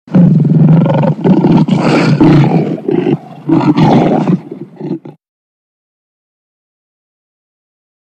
Lion roar sound ringtone free download
Animals sounds